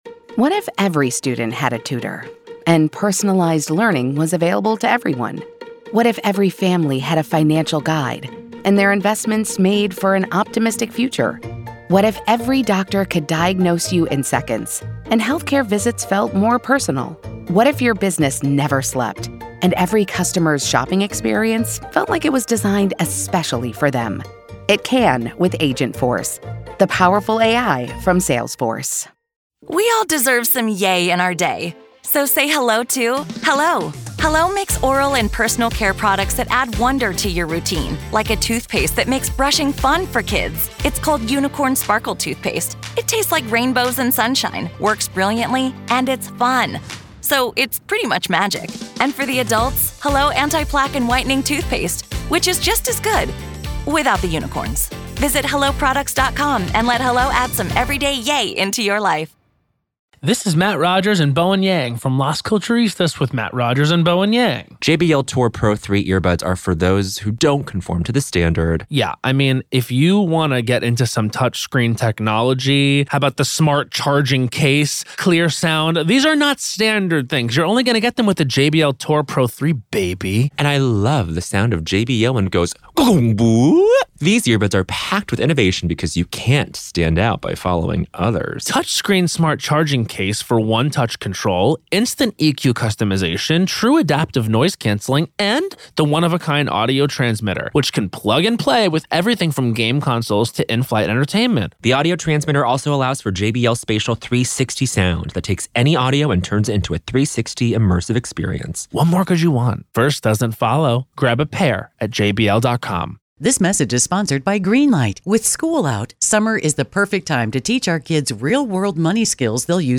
This is audio from the courtroom in the high-profile murder retrial